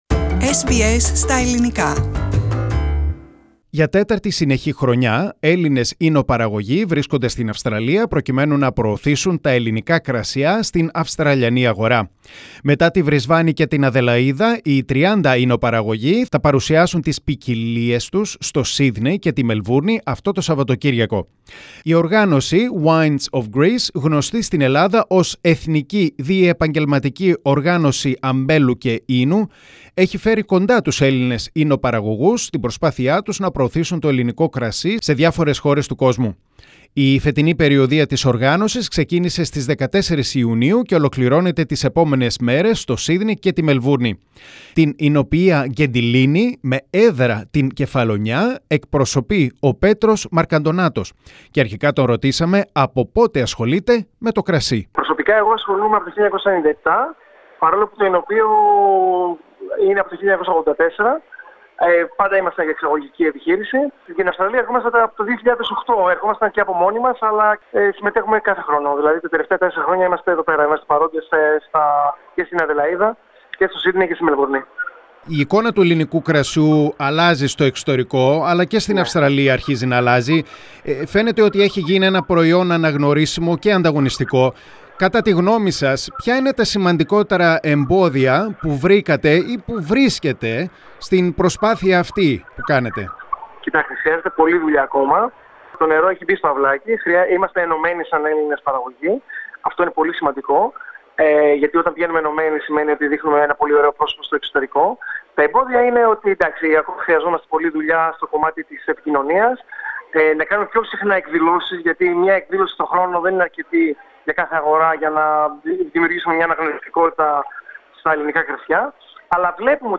SBS Greek talks to three Greek wine producers, from Kefalonia, Karditsa (Thessaly) and Ioannina (Epirus) and asked them whether it is excessive to say that Greek wines and winemaking is a "success story" in recent years, how do they see the future of the Greek wines and the most important challenges that the Greek wine producers are facing.